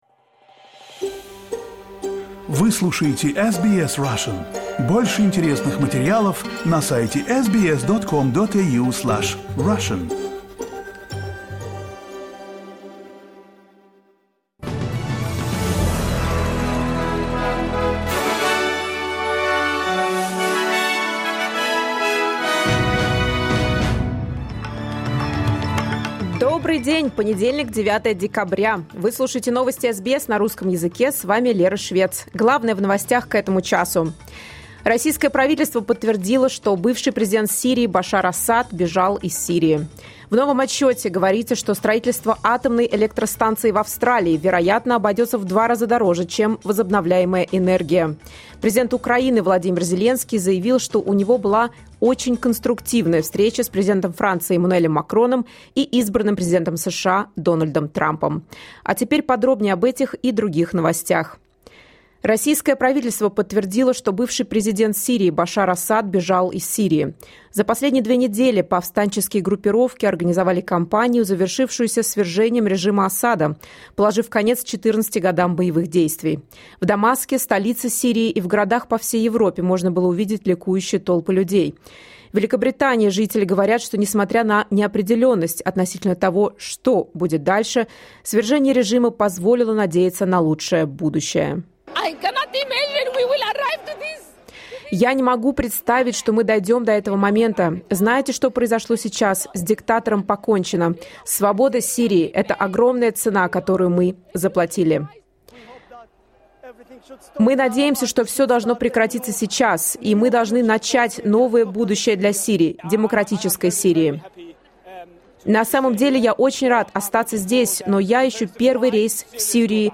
Новости SBS на русском языке — 09.12.2024